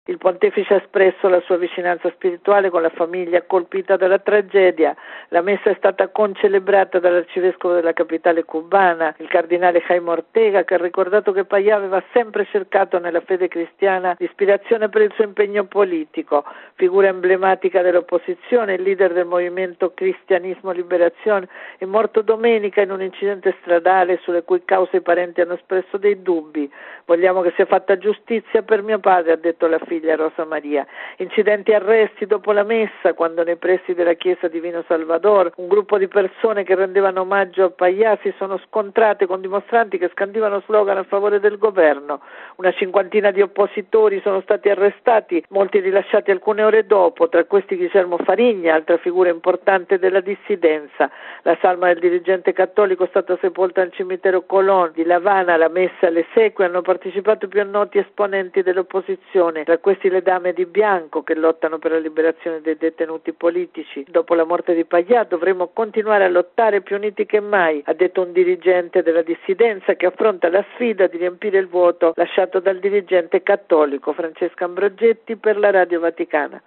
Radiogiornale del 25/07/2012 - Radio Vaticana